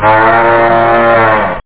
BIGCOW.mp3